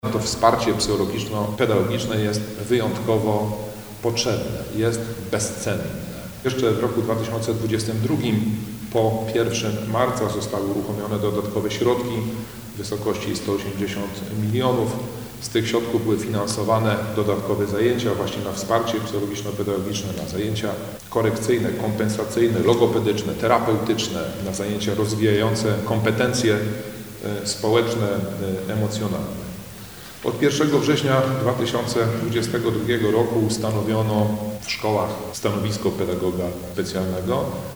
– Wsparcie psychologiczno-pedagogiczne jest niezwykle ważne, w tym świecie pełnym rozmaitych niebezpieczeństw – mówi Roman Kowalczyk, Dolnośląski Kurator Oświaty.